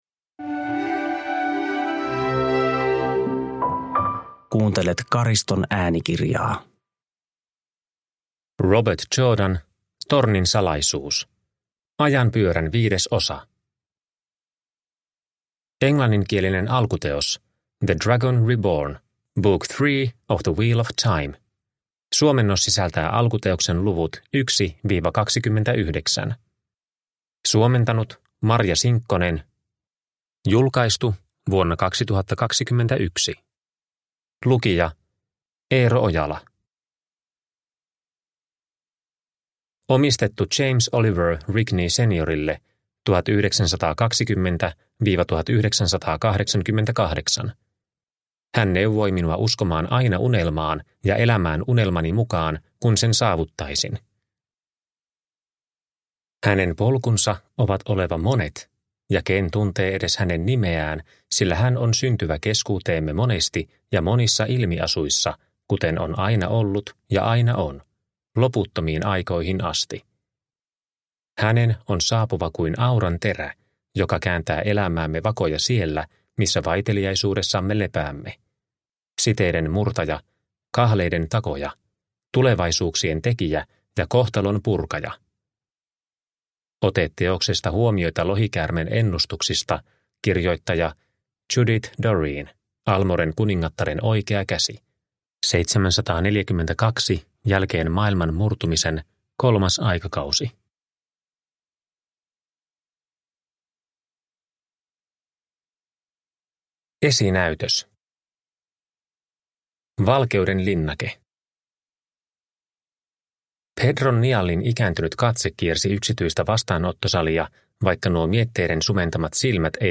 Tornin salaisuus – Ljudbok – Laddas ner